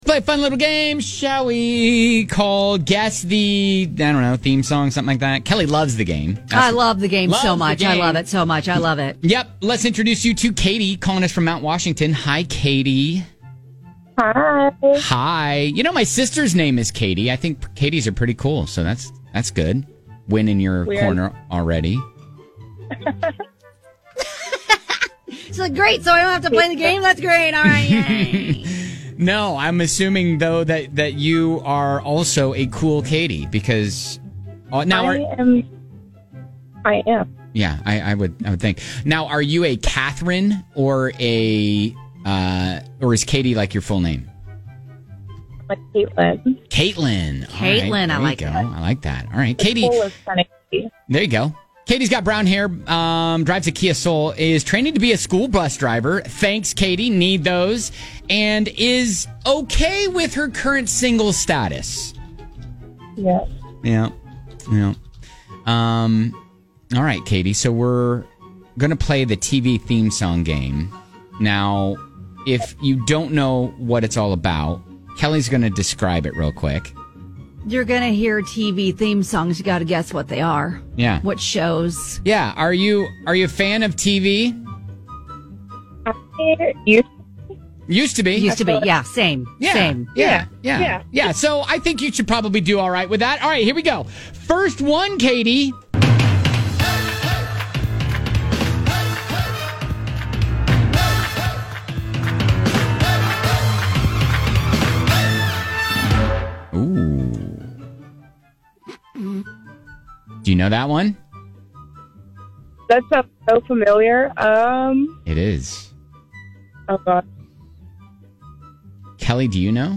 We have selected the opening theme music from several famous shows - identify them and win tickets to Jesse McCartney!